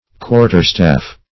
Quarterstaff \Quar"ter*staff`\, n.; pl. Quarterstaves.